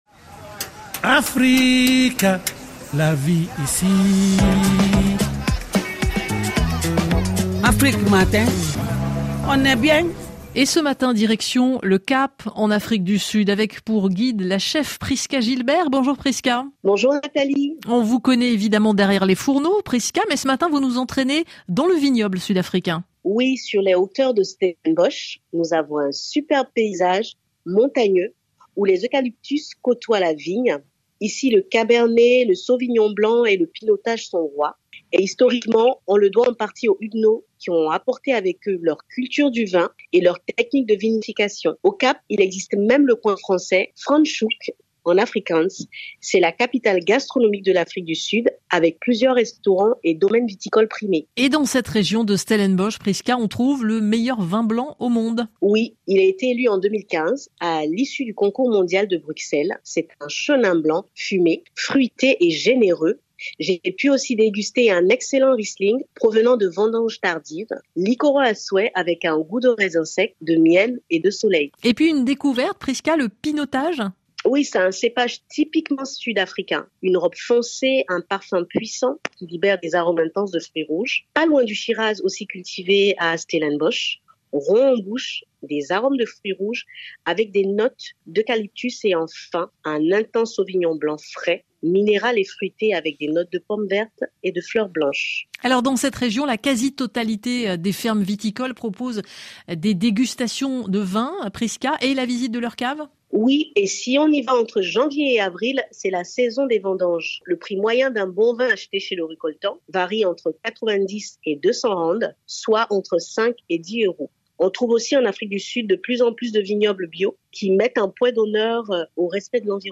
RFI – Chronique La vie ici du 14 décembre 2020 , « Dans les vignobles sud-africains »